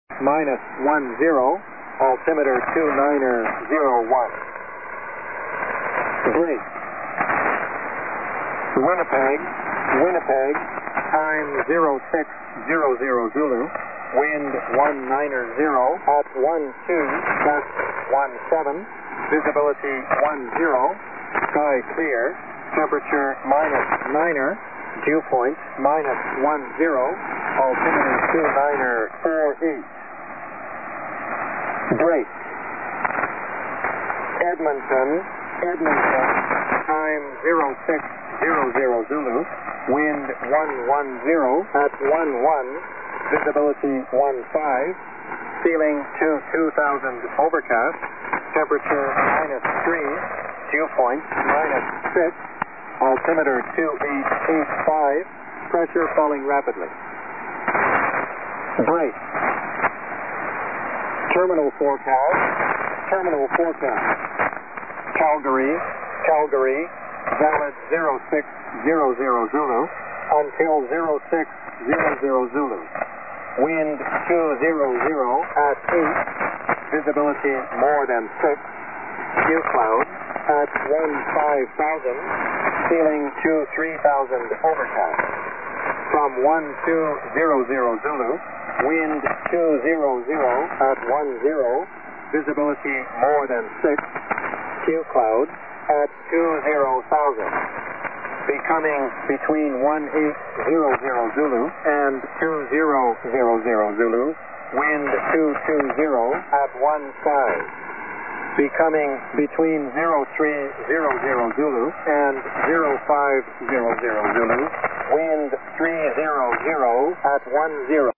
DXPedition Itatiaiuçu-MG JAN-2008
ICOM IC-R75 c/DSP + Ham Radio Deluxe
02 Antenas Super KAZ 90 graus uma da outra NORTE-SUL E LESTE-OESTE